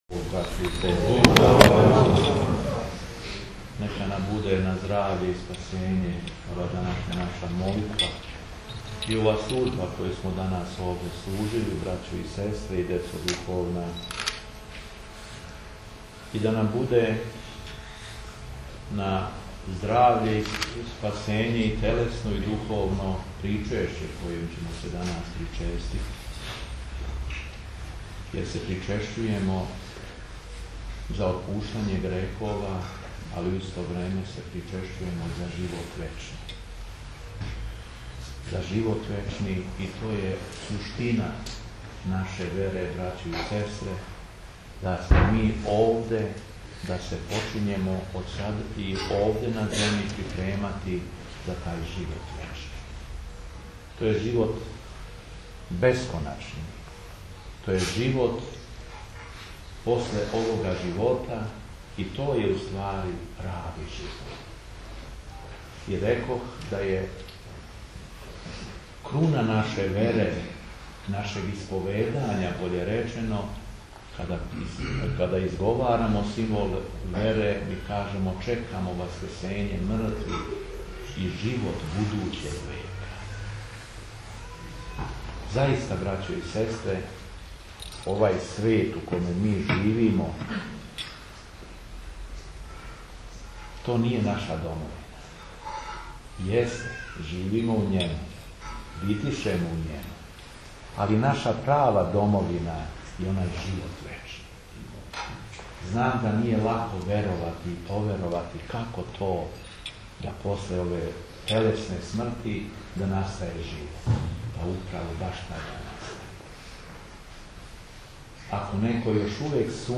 Беседа епископа шумадијског и администратора жичког Г. Јована